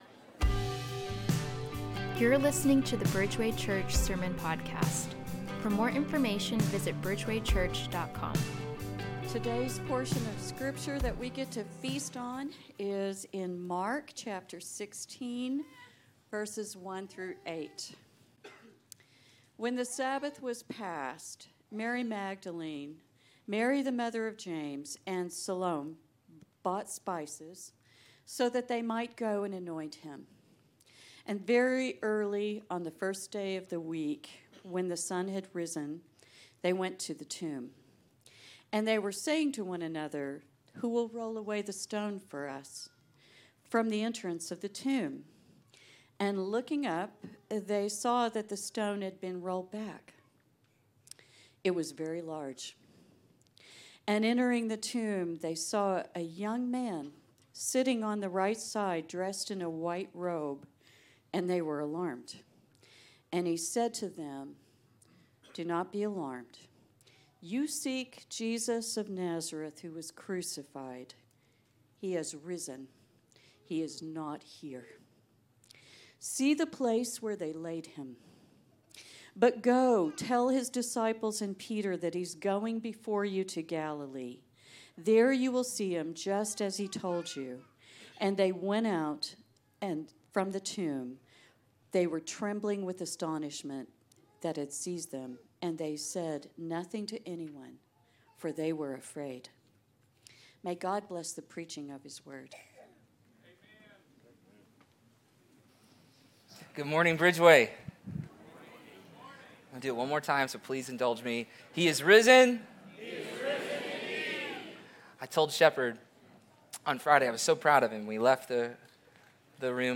Bridgeway Church Sermons